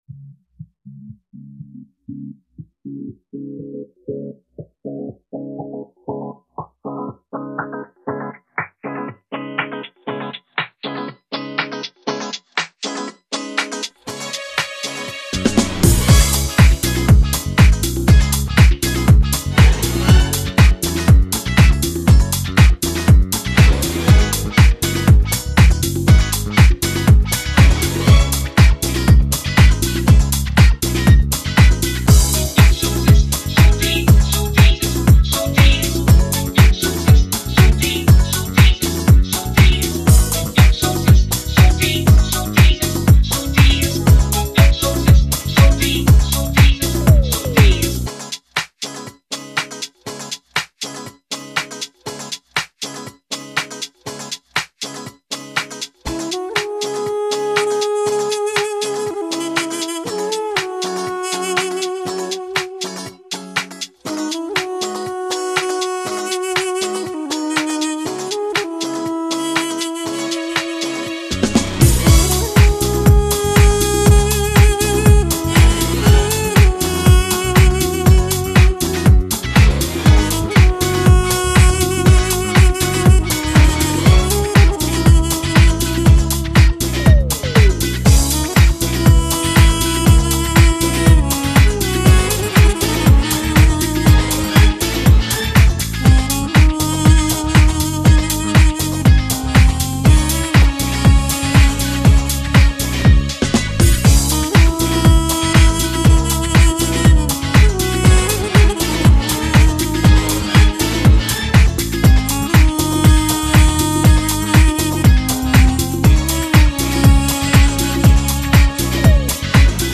Магический дудук (открыта)